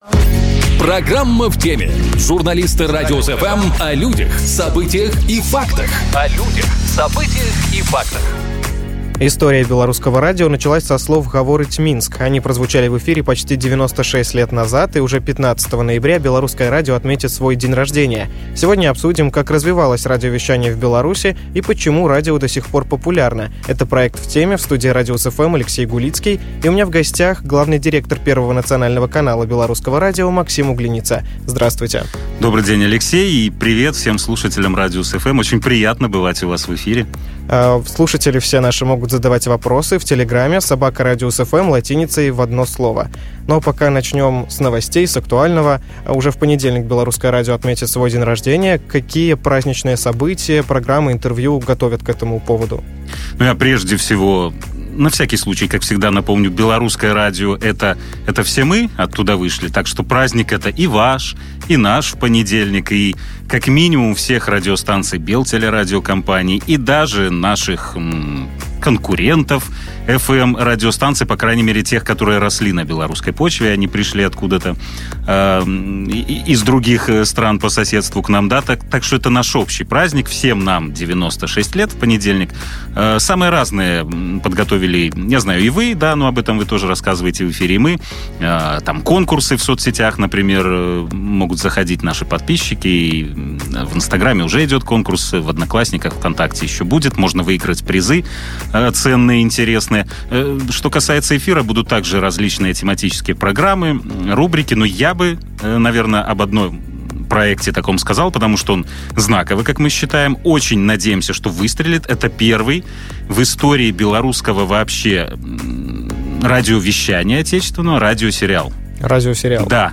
Белорусскому радио - 96 лет | Шоу с толком | Радиус-FM